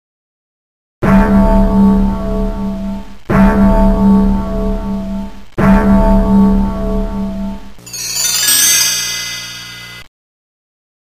La qualità di alcuni, comunque, non è ottimale.
SOUND&EFFETTI SONORI DEL PASSATO
Campane |
Campane 1.mp3